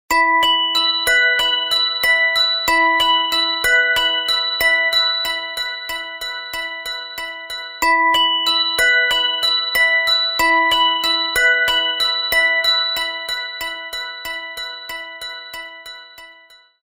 • Качество: 320, Stereo
без слов
колокольчики
звонкие
Спокойный, но очень звонкий звук.